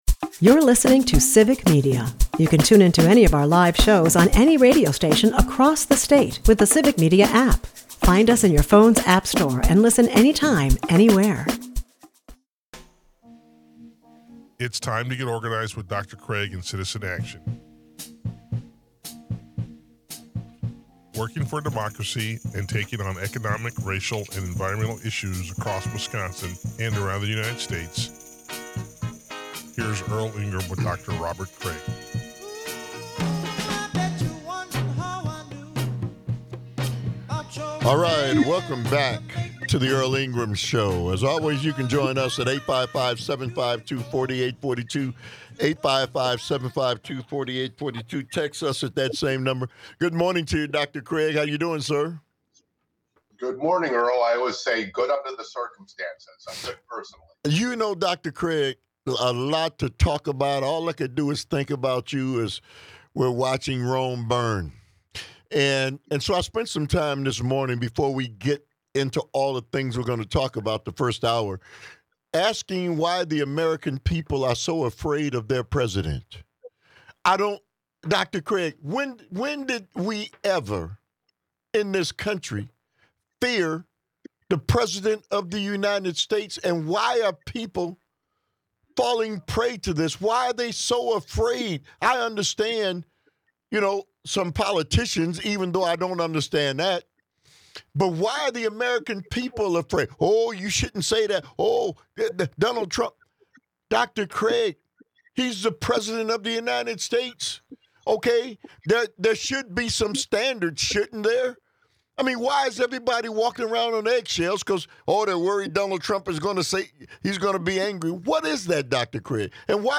Callers voice their outrage over the government's transformation into a right-wing society that targets individuals who are different.